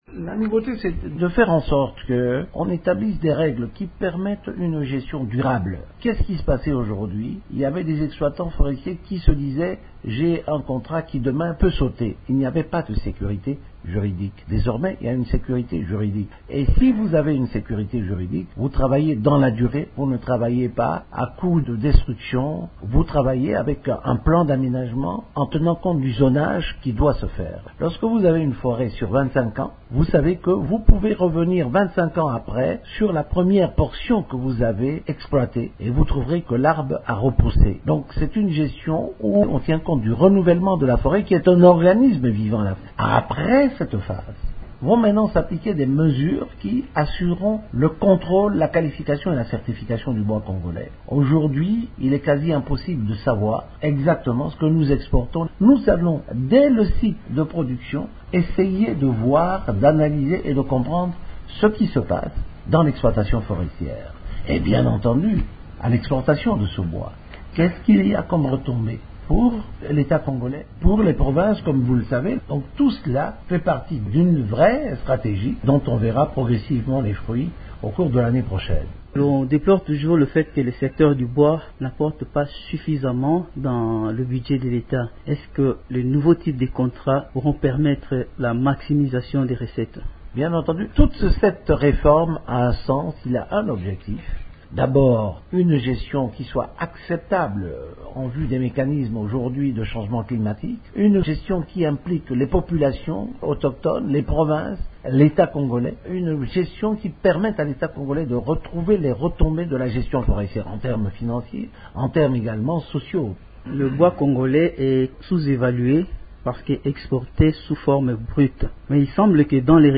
Qu’est-ce qui va changer dans la gestion du bois congolais. José Endundo, ministre de l’environnement, repond